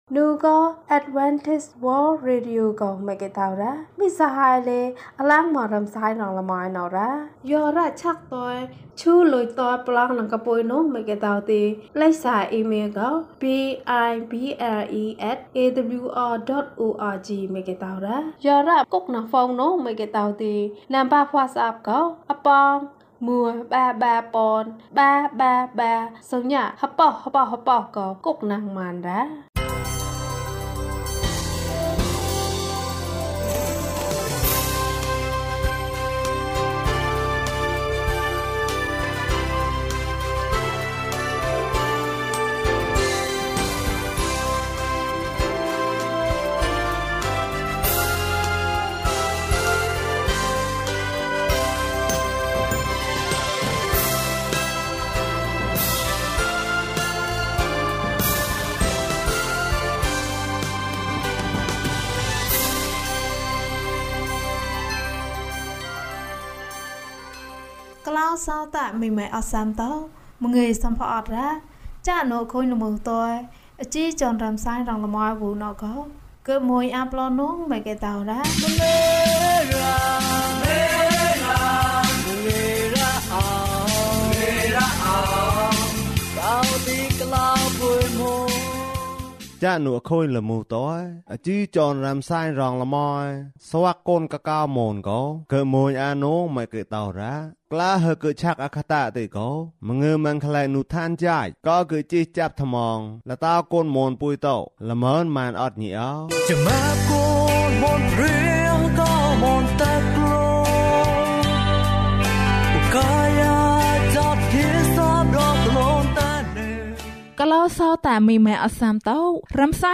ဘုရားသခင်က ငါ့ကိုဖန်ဆင်းတယ်။ ကျန်းမာခြင်းအကြောင်းအရာ။ ဓမ္မသီချင်း။ တရားဒေသနာ။